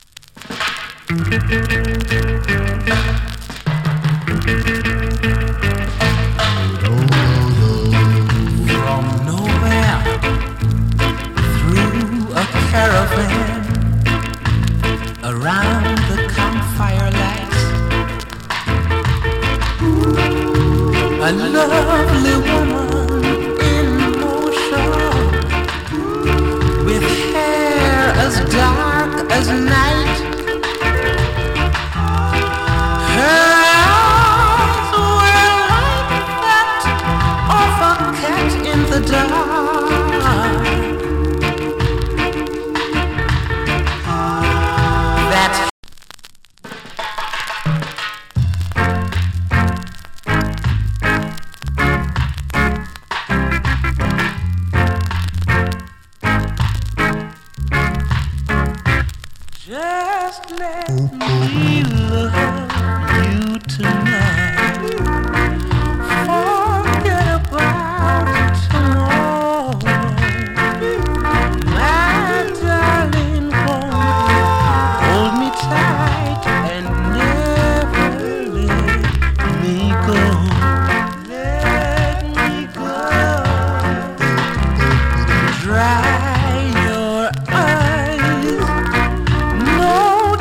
チリ、パチノイズ有り。
の KILLER ROCK STEADY !